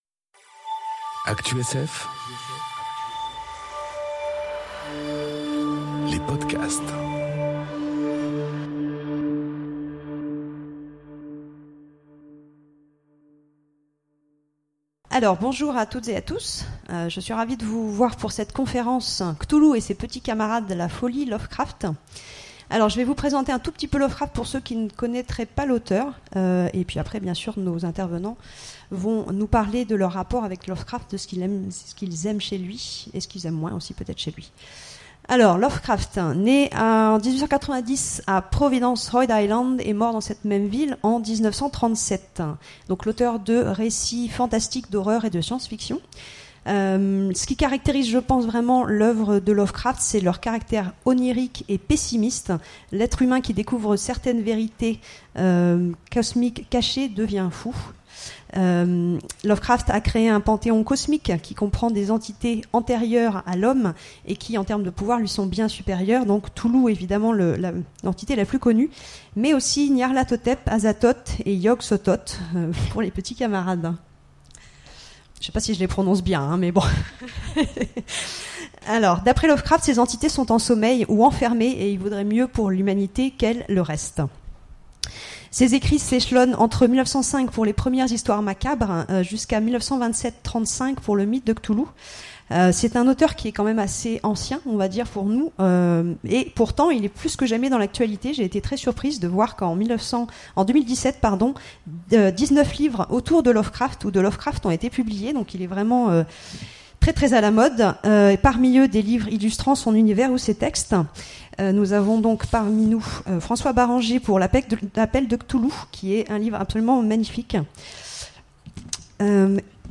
Conférence Cthulhu et ses petits camarades : la folie Lovecraft ! enregistrée aux Imaginales 2018